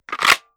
Kydex Holster 001.wav